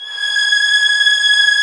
Index of /90_sSampleCDs/Roland LCDP13 String Sections/STR_Violins III/STR_Vls6 f slo